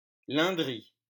Lindry (French pronunciation: [lɛ̃dʁi]